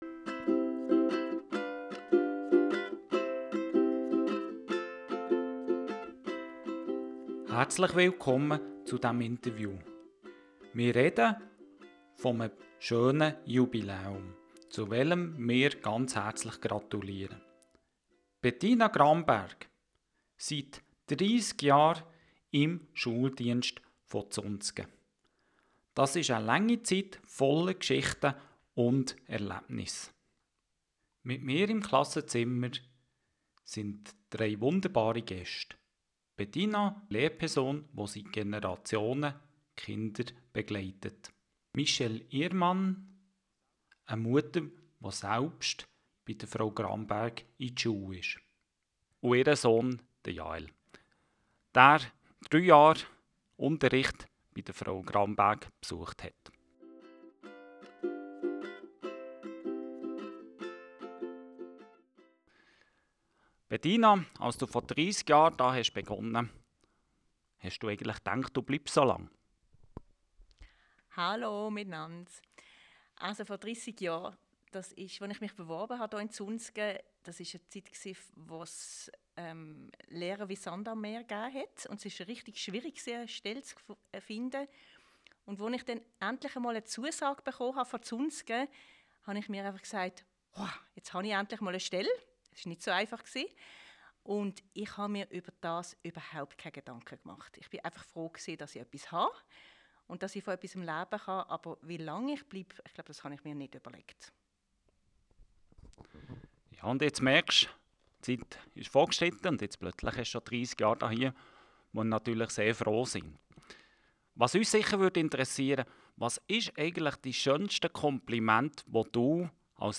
Ein besonderes Interview
Besonders berührend: Mit dabei sind eine Mutter mit Sohn.